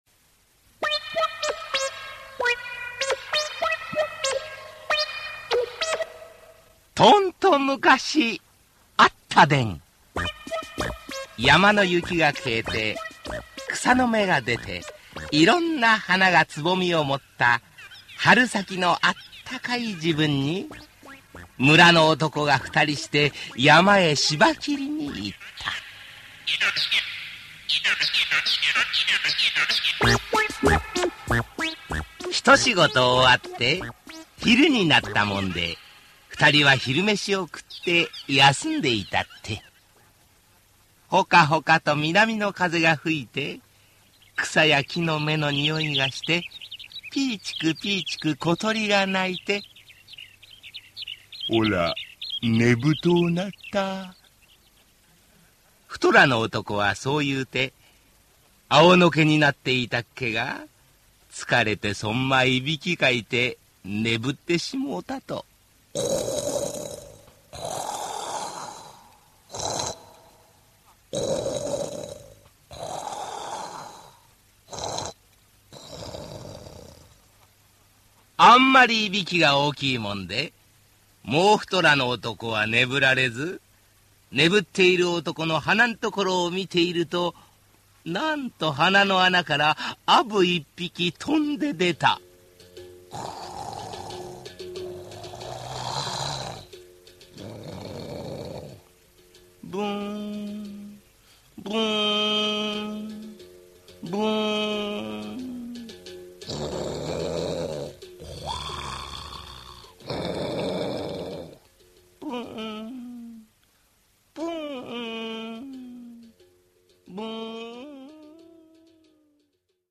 [オーディオブック] 白つばきの夢